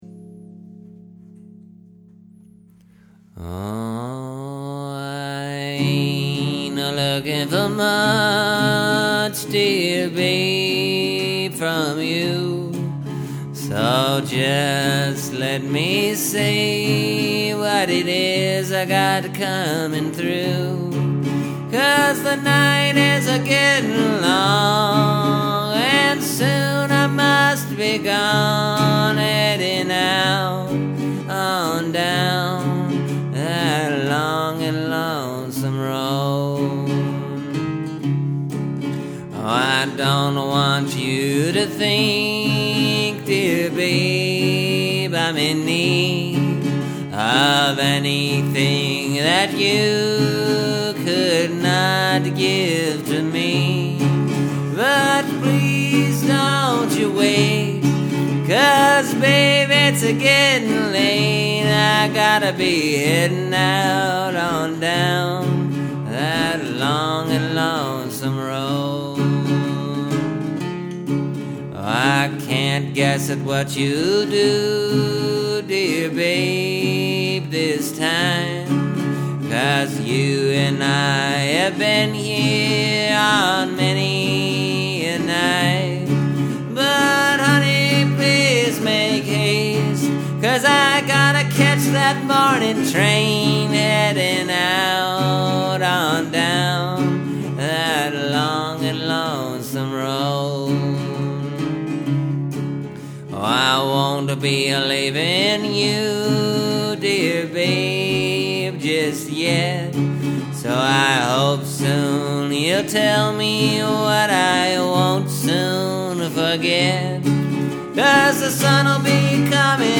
It’s just a simple little song.